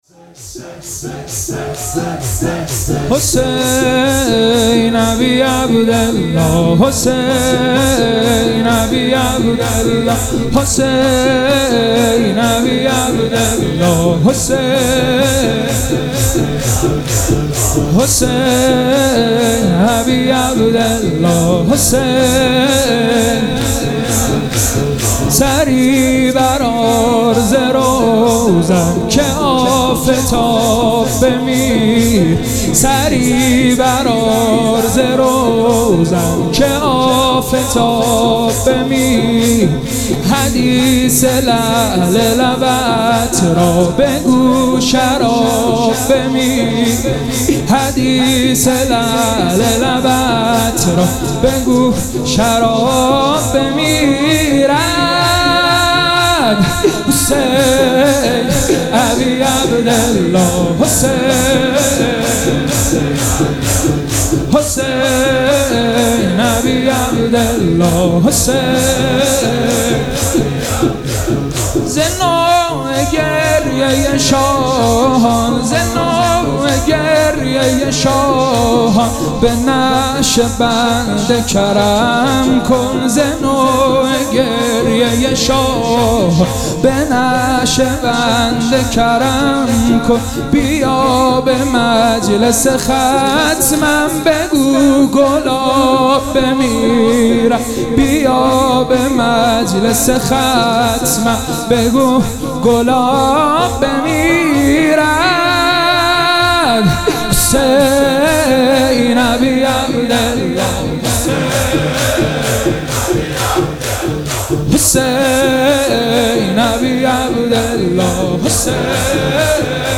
مراسم عزاداری شب شهادت امام حسن مجتبی(ع)
شور